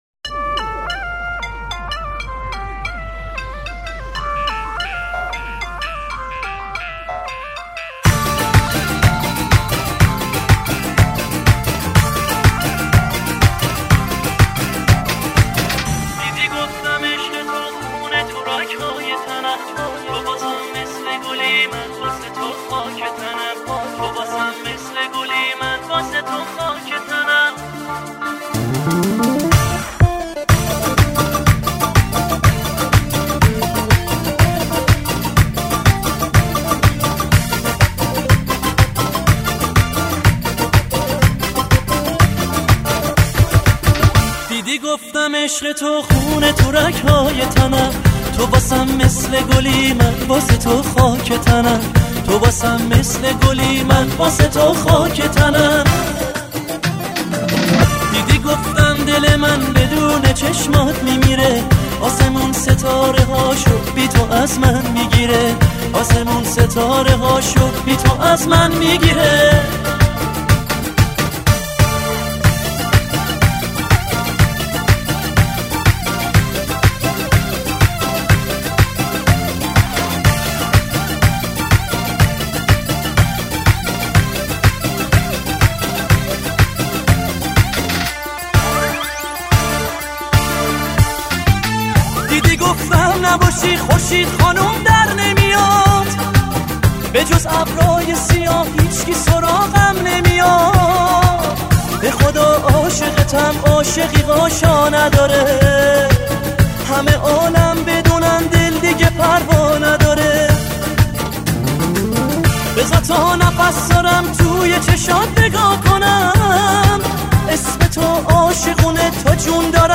بخش دانلود آهنگ شاد آرشیو